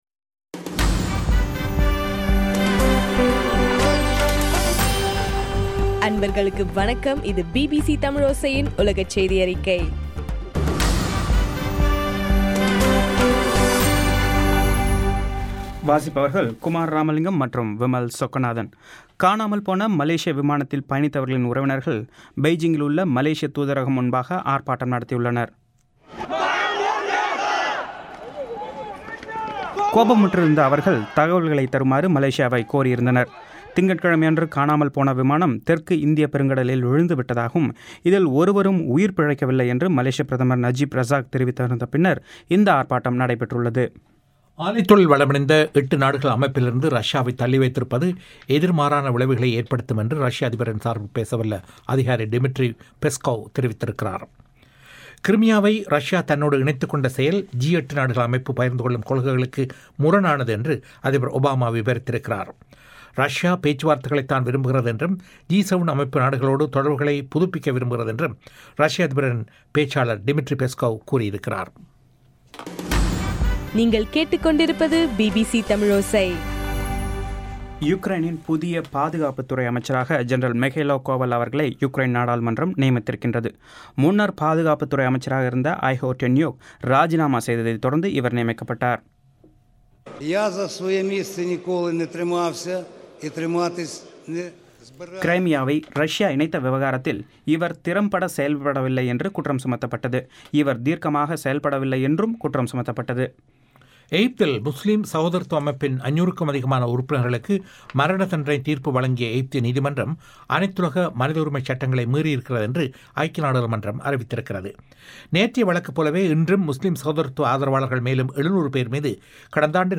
இன்றைய ( மார்ச் 25) பிபிசி தமிழோசை உலகச் செய்தி அறிக்கை